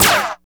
06.3 SNARE.wav